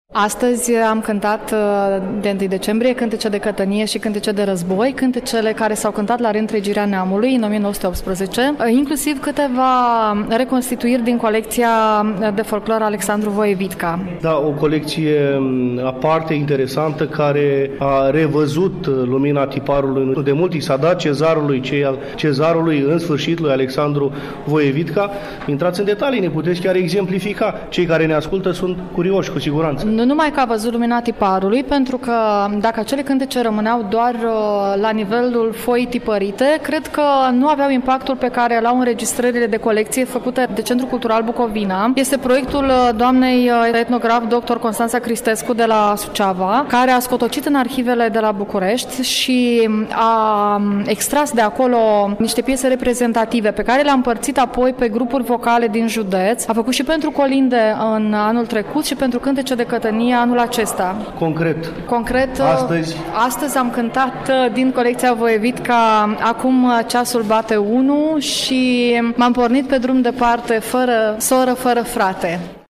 Plini de emoție, pe fundalul unirii, dialogăm cu câțiva membri ai Grupului Vocal Bărbătesc Flori de Măr din Râșca, județul Suceava, pe care i-am întâlnit, la Iași, în incinta Muzeului Unirii, de ziua națională a României, care au venit cu sufletele deschise să încălzească sufletele românilor, combinând sărbătoarea cu tradiția, intrepretând cântece dedicate, din inimă, celor care au luptat pentru glia străbună.